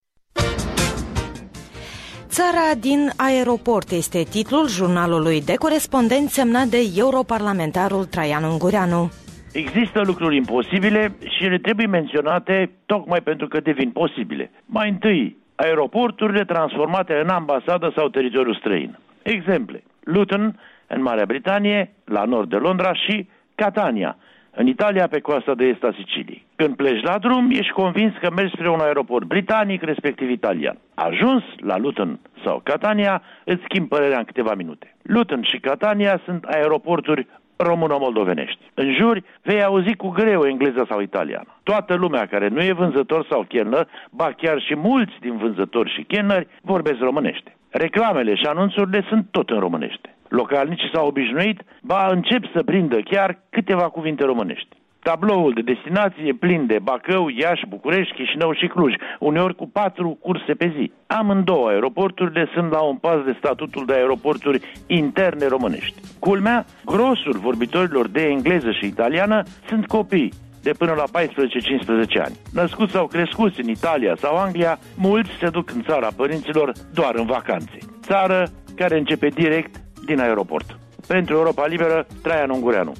Jurnal de corespondent: Traian Ungureanu (Londra)